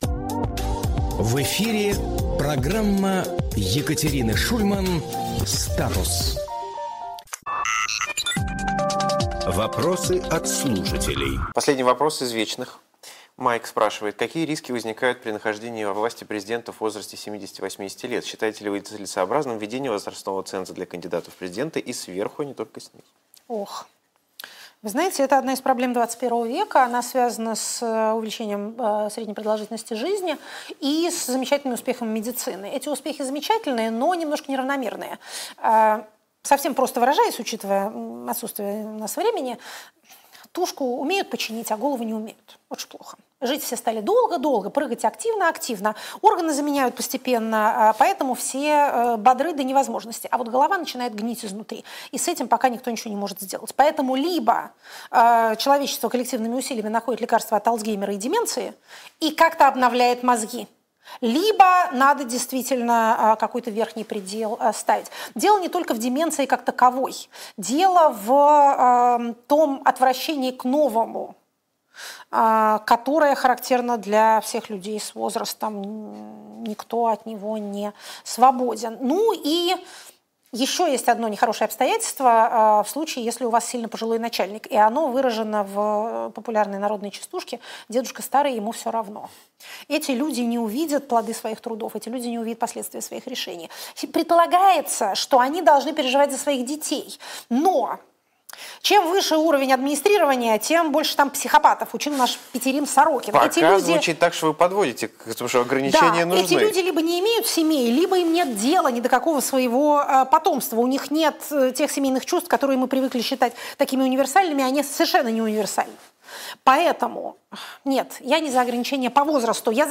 Екатерина Шульманполитолог
Фрагмент эфира от 13.01.2026